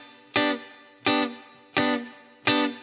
DD_TeleChop_85-Gmaj.wav